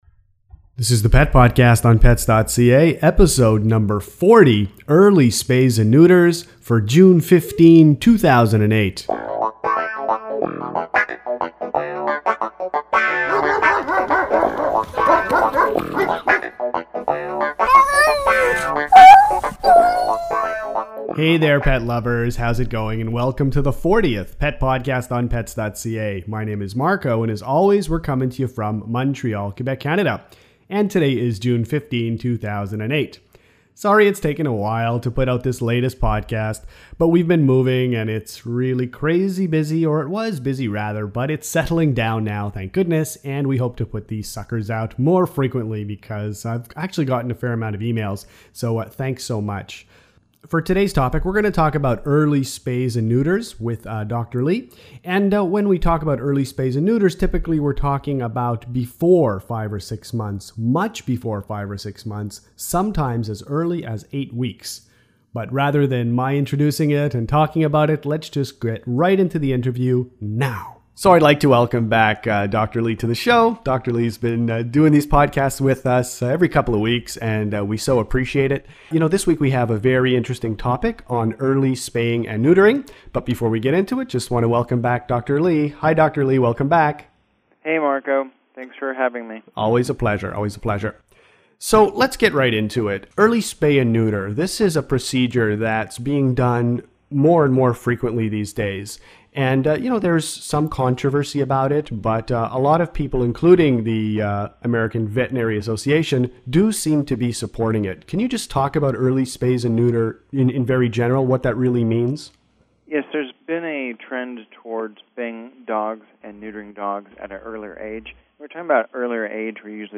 Early spay and neuter (8-16 weeks) – Pet podcast #40 – Interview